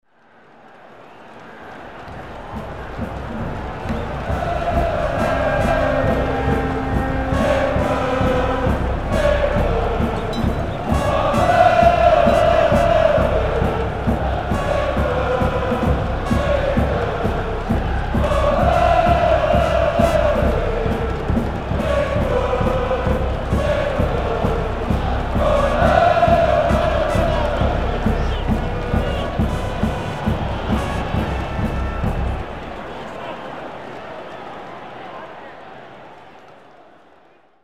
Футбольные кричалки